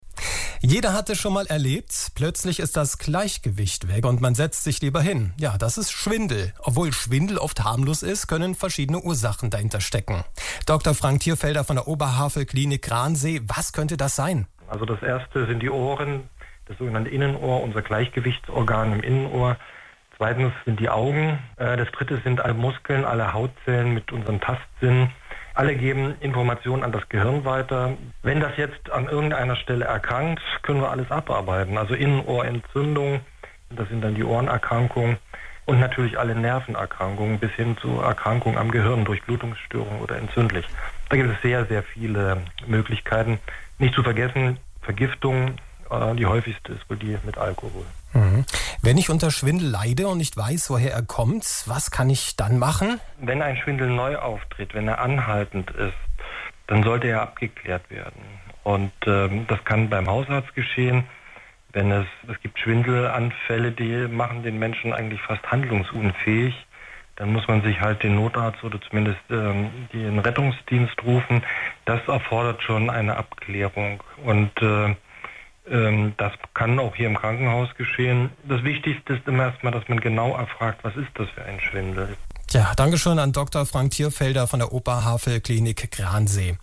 im Interview bei Radio B2.